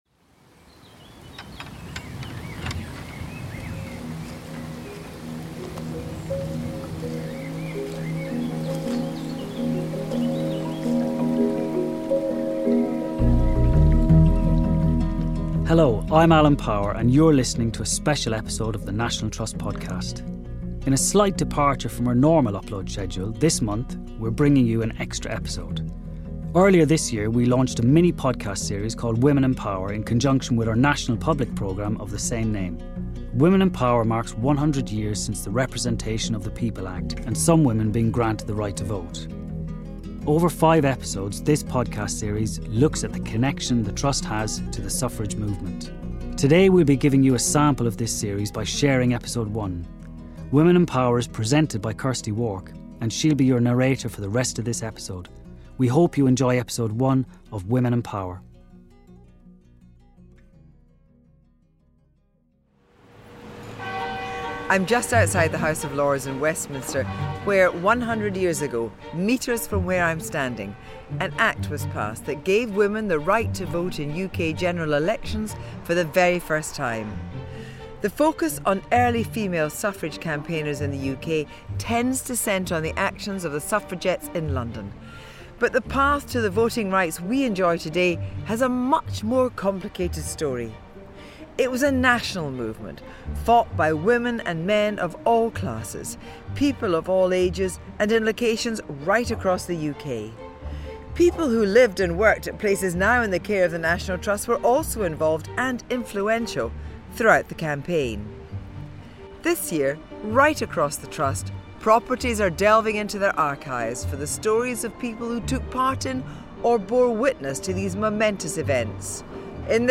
To fill the gap until our next regular episode on the first Thursday of next month, we thought you might like to hear the first episode of a mini series we launched earlier this year called Women and Power. Women and Power looks at the Trust’s links to the suffrage movement and is narrated by broadcaster and Journalist Kirsty Wark.